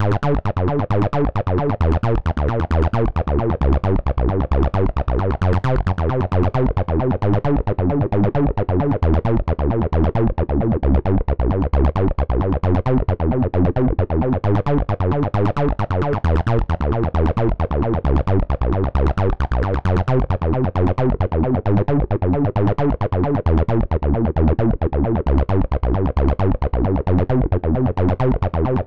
• arp break bass acid 133 bpm - Em.wav